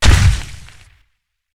large_step2.ogg